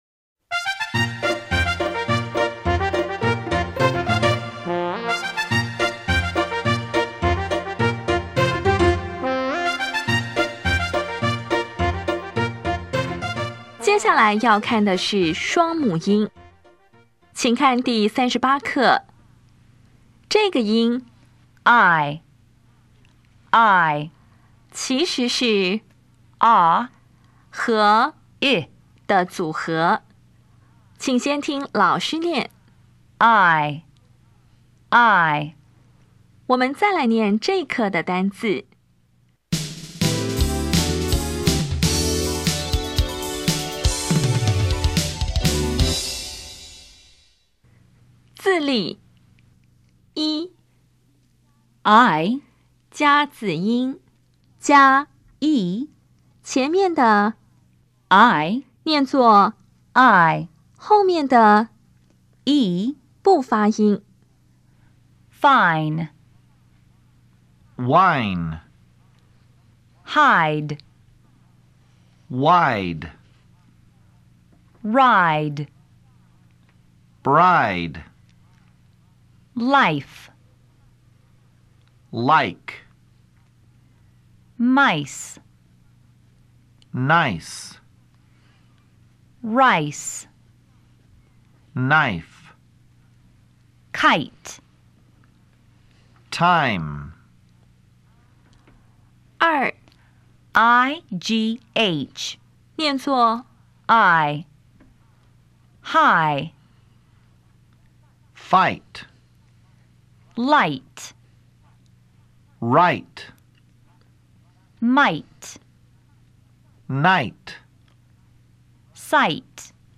当前位置：Home 英语教材 KK 音标发音 母音部分-3: 双母音 [aɪ]
音标讲解第三十八课
比较 [e][aɪ]